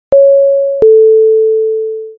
chime_1.mp3